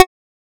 edm-perc-13.wav